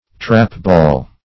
Meaning of trapball. trapball synonyms, pronunciation, spelling and more from Free Dictionary.
Trapball \Trap"ball`\, n. An old game of ball played with a trap.